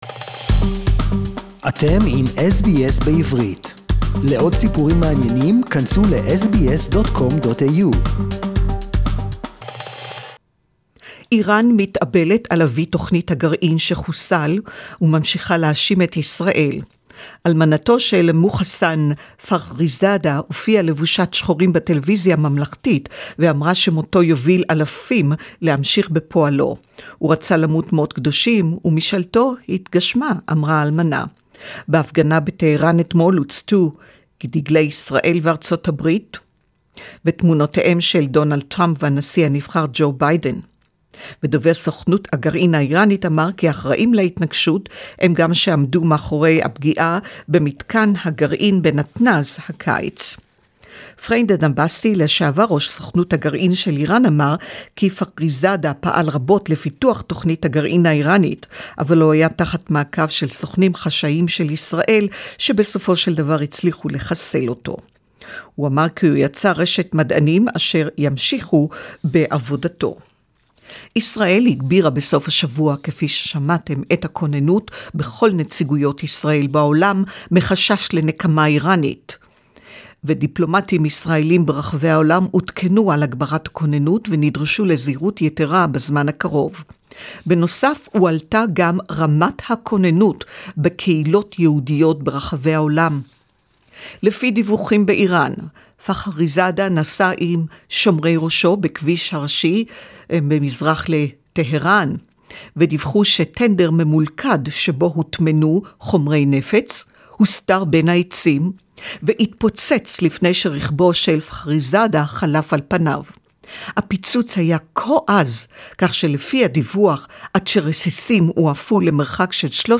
News reports claim that she was detained in Iran due to her Israeli partner. 24 hrs. after she reached Australian soil, a top nuclear scientist suspected of masterminding Iran’s nuclear program was assassinated in day light in the suburbs of Teheran. A special report in Hebrew